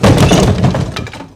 crateBreak2.ogg